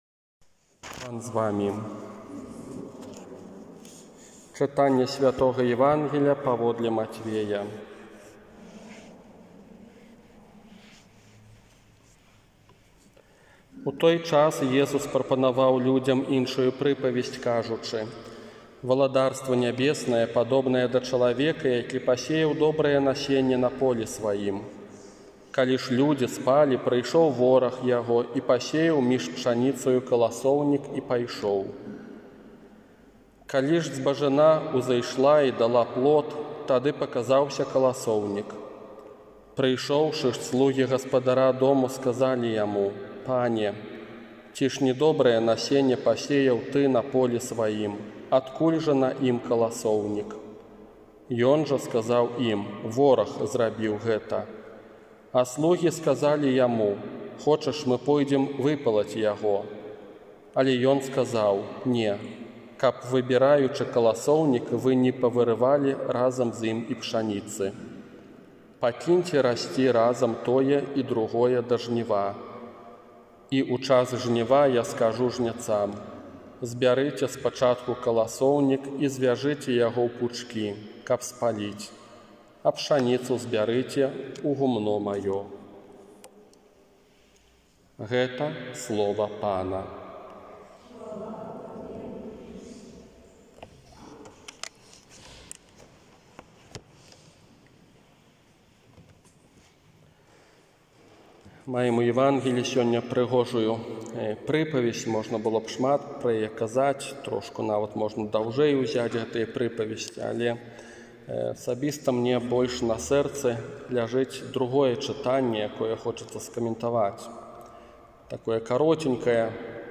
Казанне на шаснаццатую звычайную нядзелю 19 ліпеня 2020 года
Калi_не_ведаем_як_малiцца_казанне.m4a